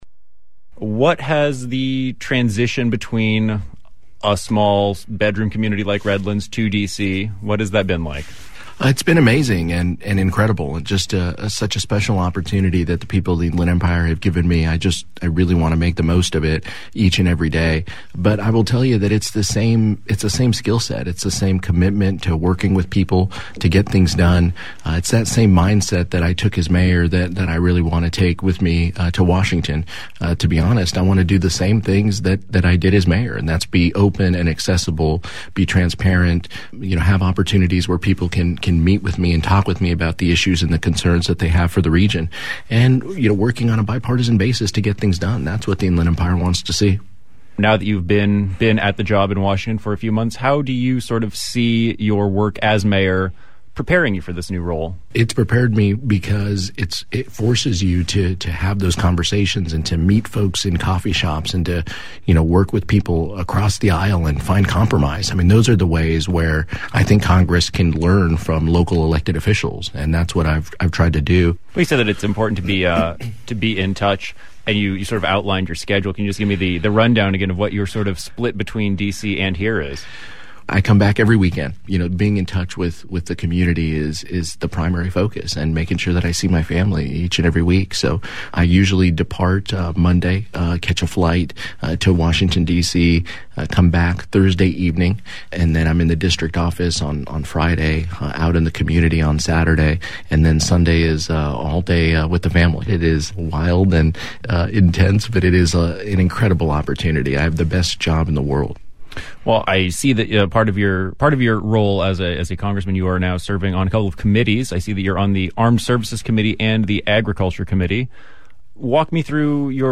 This is part 1 of a 2-part interview.